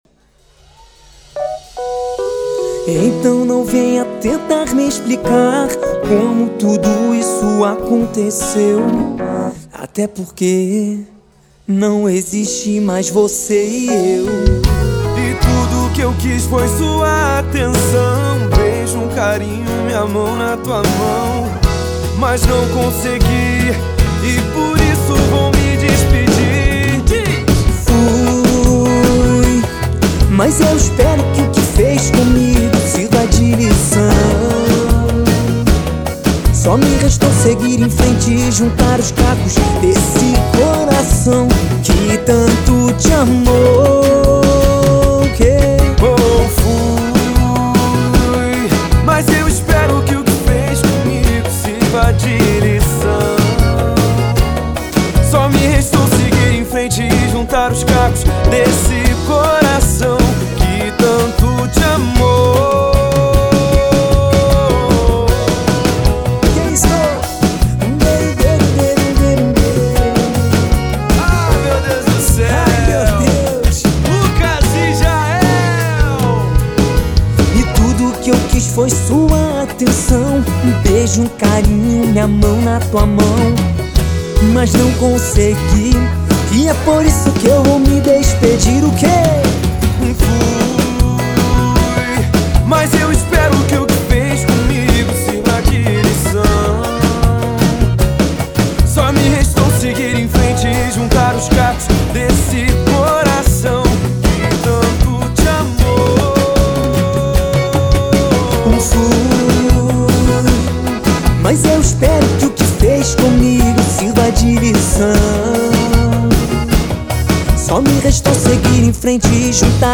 • Ao Vivo